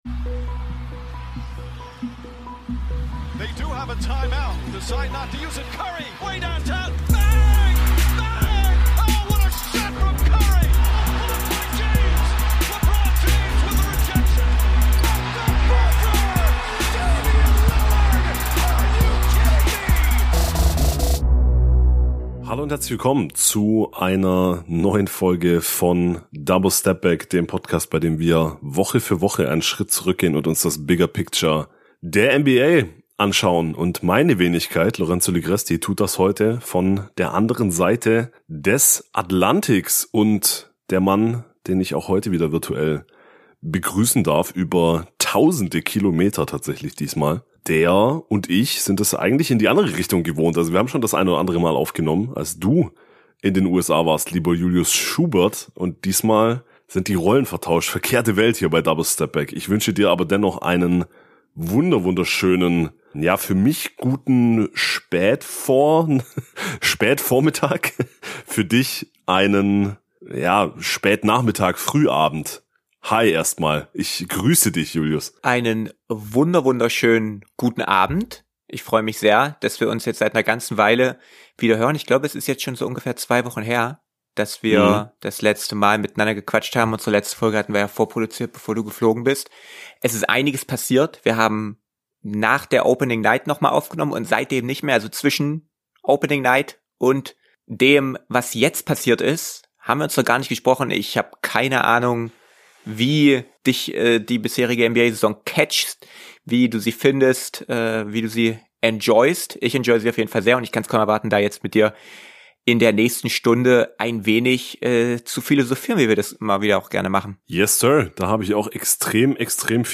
Und nach der regulären Folge könnt ihr die Statements von Giannis Antetokounmpo und Myles Turner nach dieser Partie in voller Länge hören.